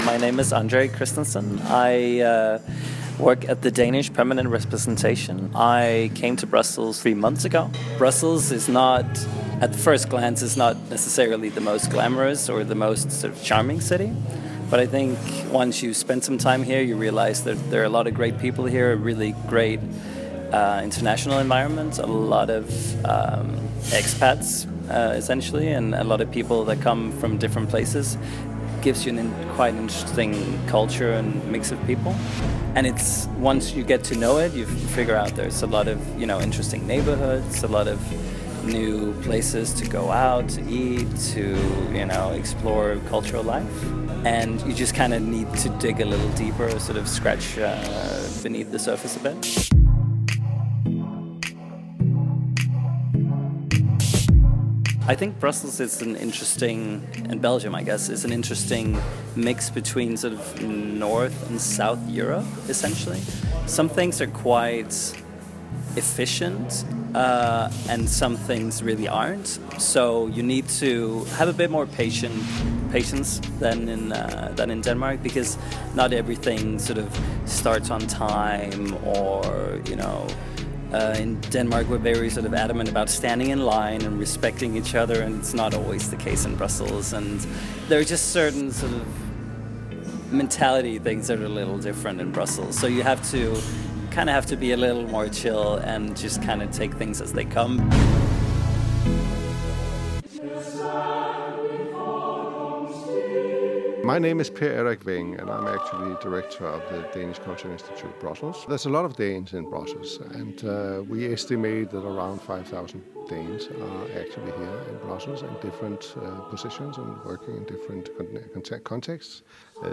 We meet two Danish expats to find out about the tradition.